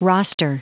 Prononciation du mot roster en anglais (fichier audio)
Prononciation du mot : roster